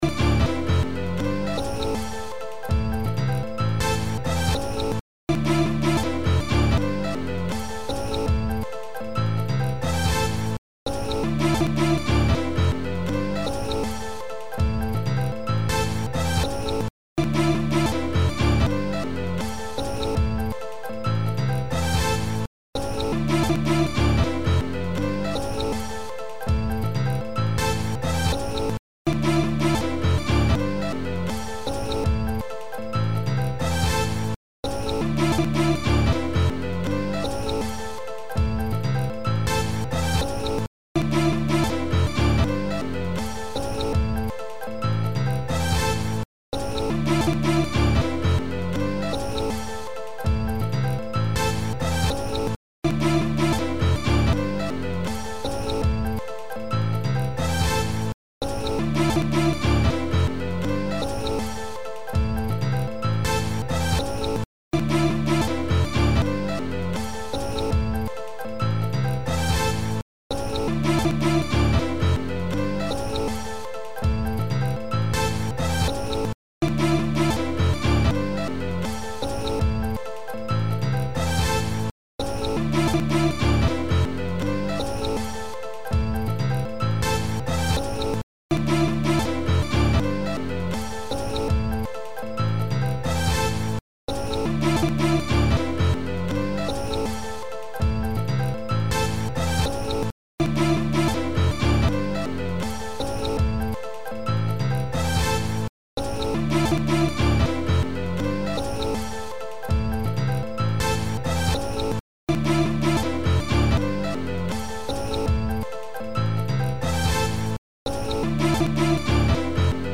ახალი ამბები 13:00 საათზე –09/02/21 – HeretiFM